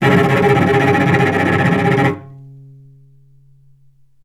healing-soundscapes/Sound Banks/HSS_OP_Pack/Strings/cello/tremolo/vc_trm-C#3-mf.aif at b3491bb4d8ce6d21e289ff40adc3c6f654cc89a0
vc_trm-C#3-mf.aif